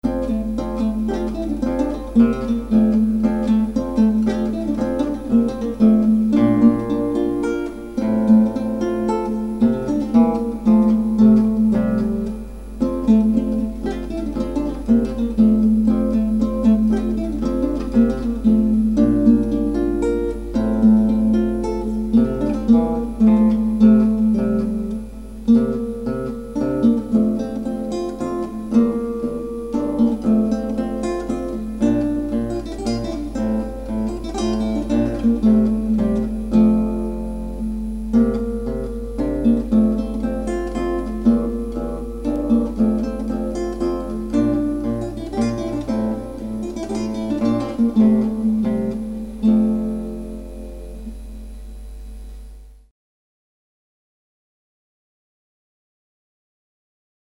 Audios Clásicos